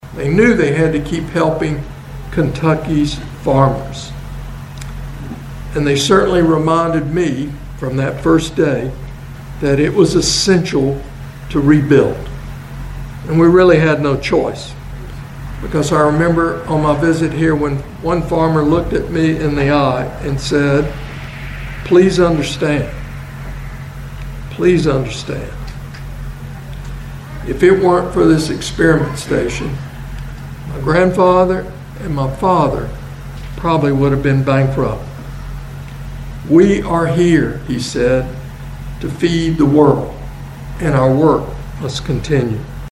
The University of Kentucky leadership and board of trustees were joined by farmers, state and local officials, and community members to celebrate a century of service at the Research and Education Center in Princeton.